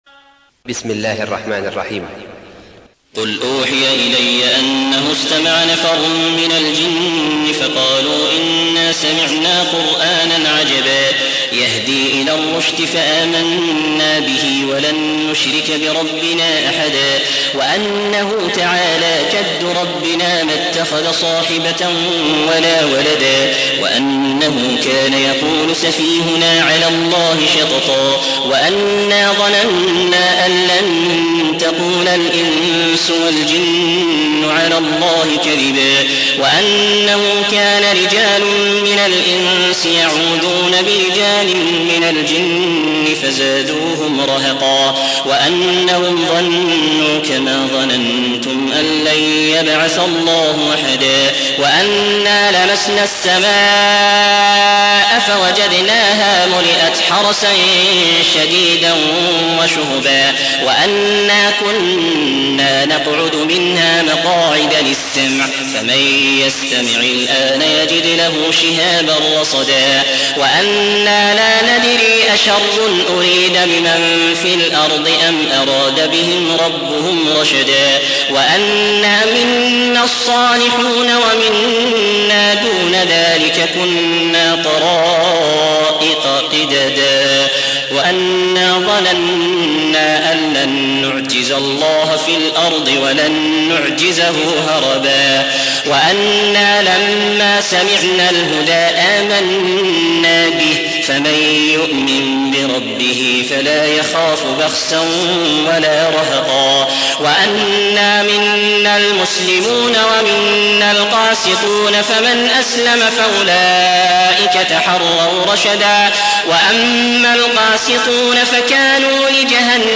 72. سورة الجن / القارئ